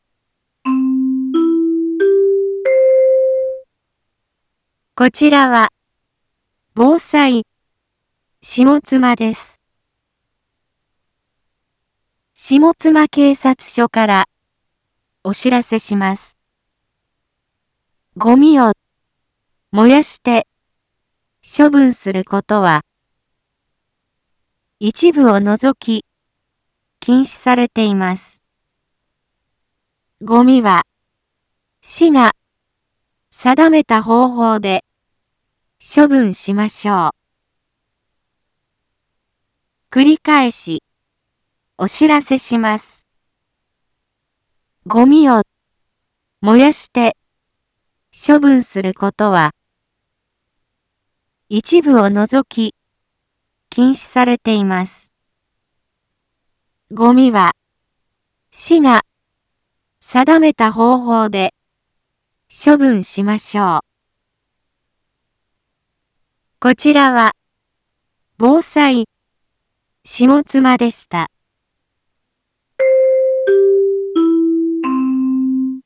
一般放送情報
Back Home 一般放送情報 音声放送 再生 一般放送情報 登録日時：2024-11-25 10:01:21 タイトル：ごみの野焼き禁止（啓発放送） インフォメーション：こちらは、ぼうさいしもつまです。